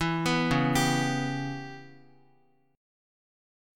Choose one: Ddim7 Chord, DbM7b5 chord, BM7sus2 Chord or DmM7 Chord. DbM7b5 chord